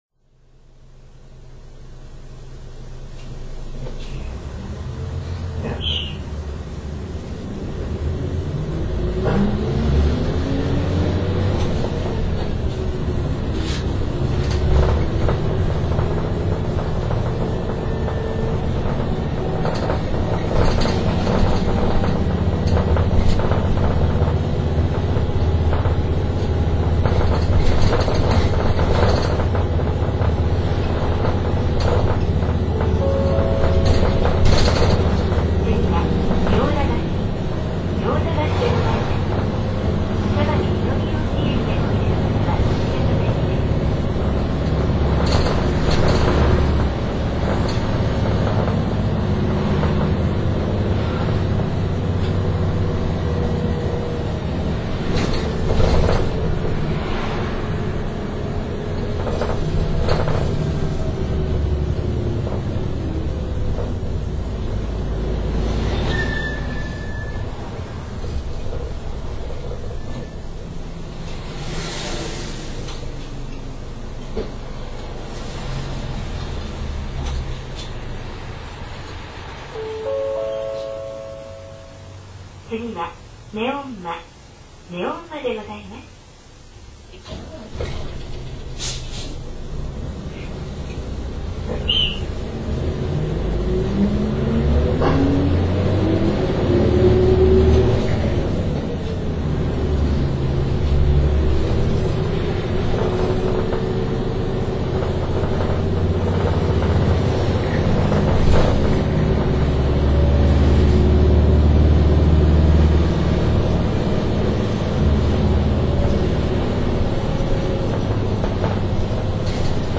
走行音的にはこもった感じの6D24よりも低音が若干強くなり、
シフトアップ時などに心地良いヒューン音が聴かれるなどといった特徴があります。
長１６系統　西用田〜杉久保